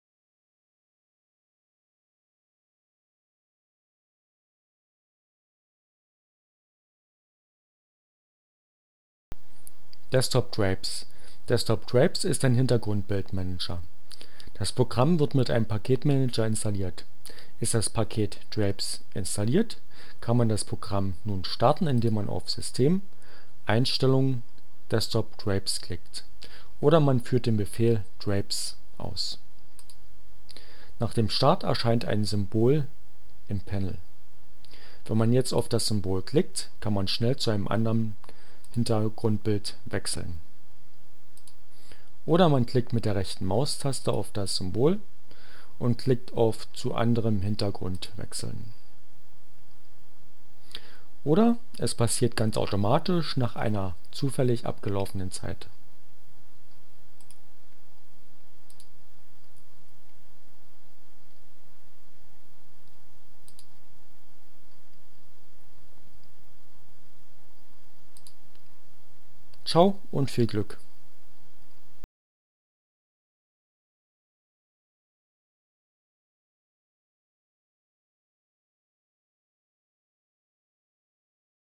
Tags: CC by-sa, Gnome, Linux, Neueinsteiger, Ogg Theora, ohne Musik, screencast, ubuntu, Desktop Drapes, wallpaper